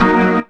B3 EMIN 1.wav